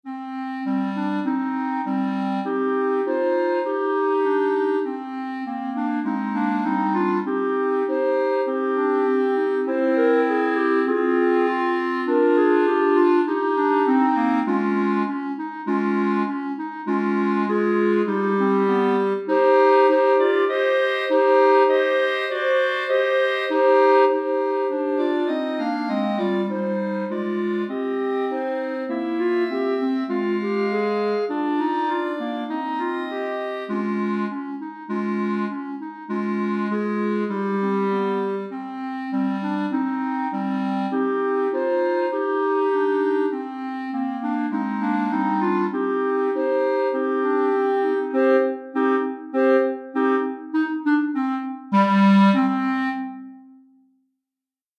Répertoire pour Clarinette - 3 Clarinettes en Sib